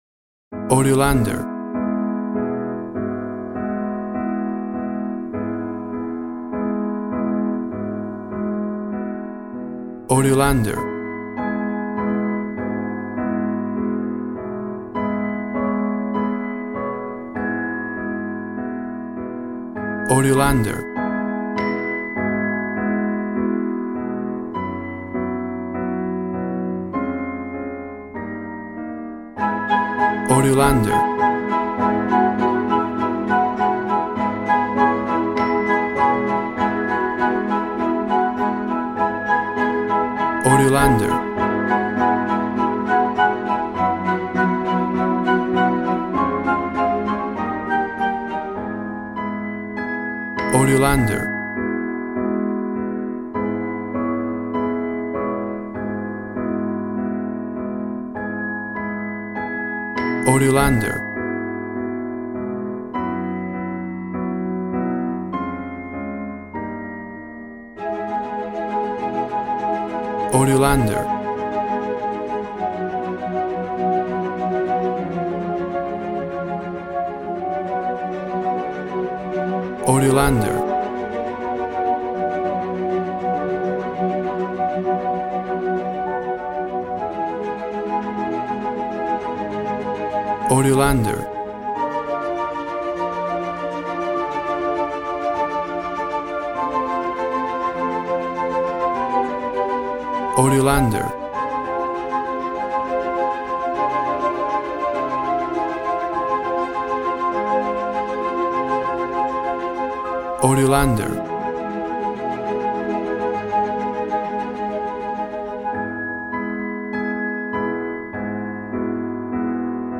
Regal and romantic, a classy piece of classical music.
Tempo (BPM) 100